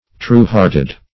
\True"-heart`ed\